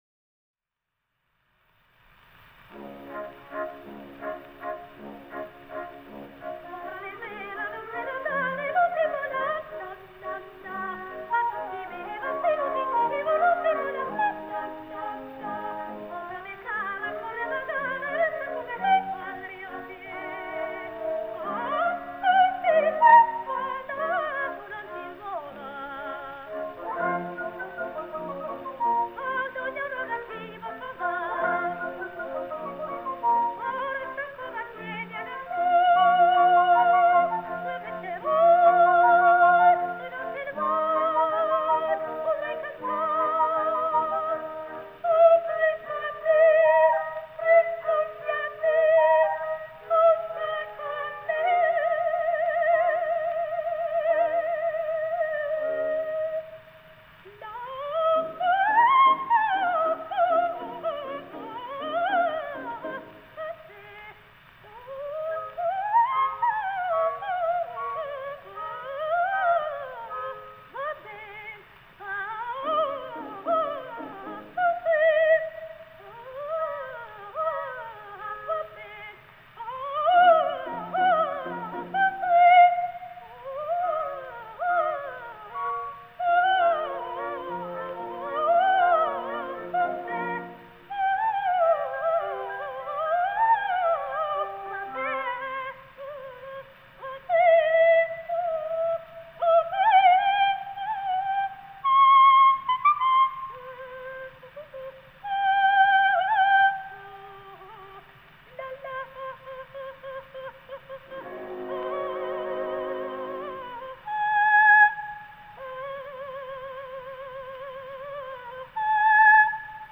ТЕТРАЦЦИНИ (Tetrazzini) Луиза (29, по др. данным, 28 VI 1871, Флоренция - 28 IV 1940, Милан) - итал. певица (колоратурное сопрано).